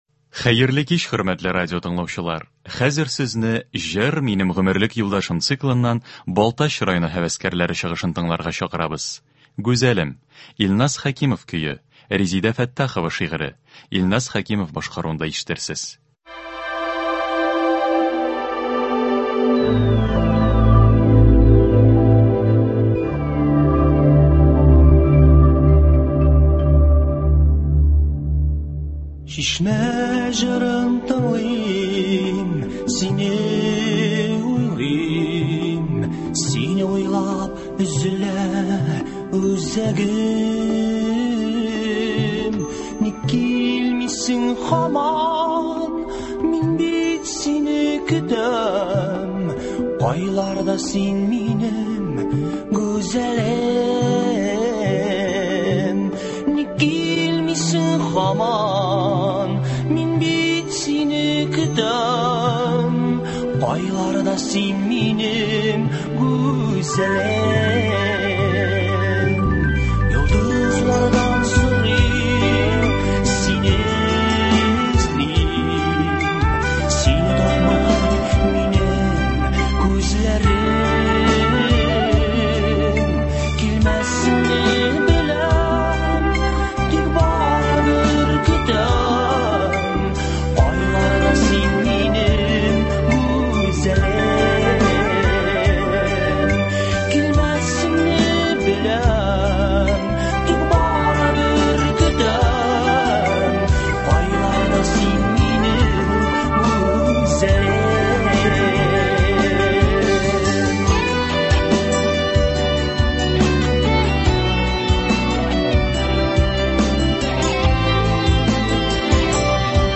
Концерт (24.05.21)